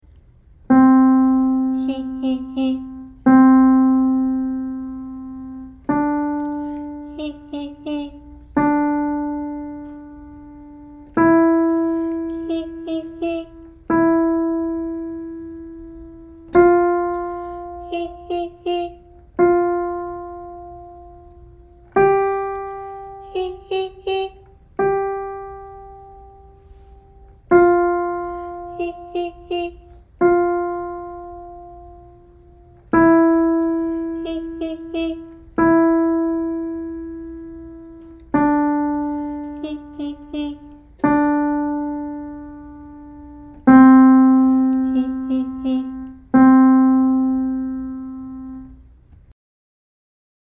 地声を後ろ上方向（後頭部の方向）に出す
小声の地声「ヒ」で発声します。
裏声に聞こえるかもしれませんが、しっかりと小さい「地声」を出すように意識してみてください。
後ろ上の地声（新）.mp3